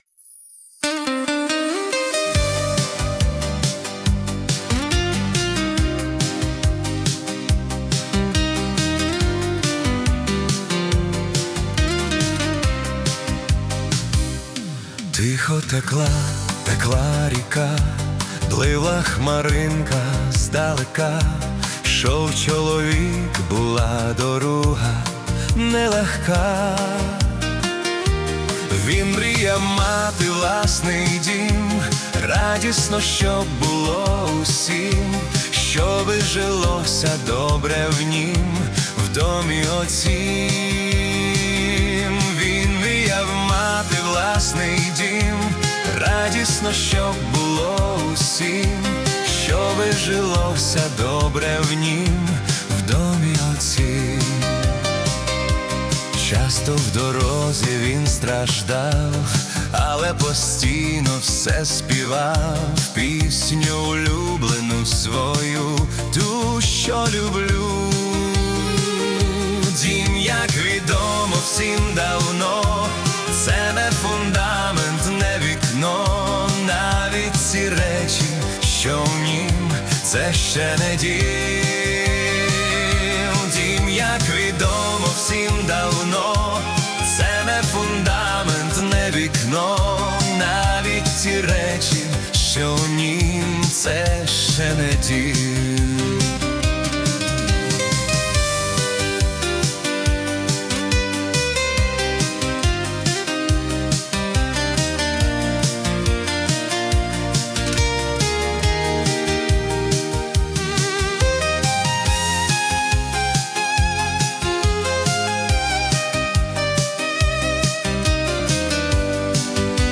Глибока духовна пісня про життєву дорогу та вічний Дім.
Тиха ріка, життєвий шлях, духовна пісня, пошук дому